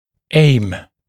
[eɪm][эйм]цель, замысел; стремиться к (чему-л.), нацеливаться